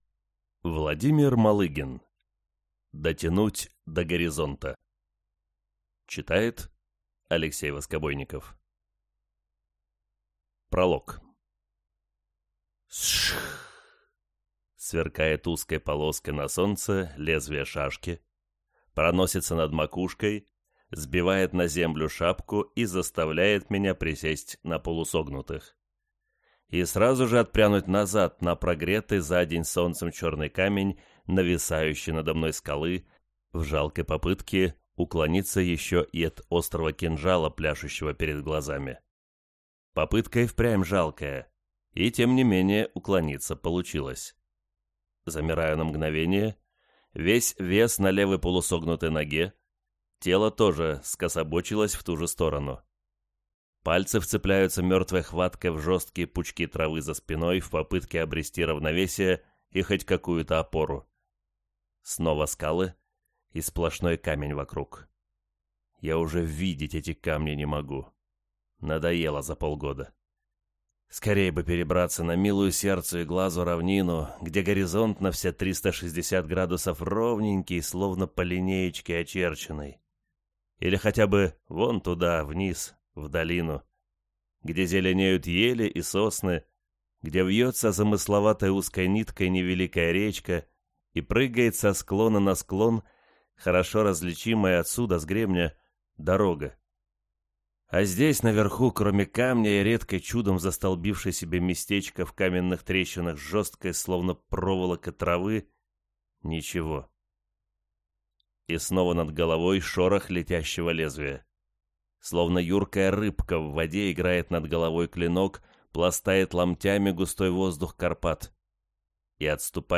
Аудиокнига Дотянуть до горизонта | Библиотека аудиокниг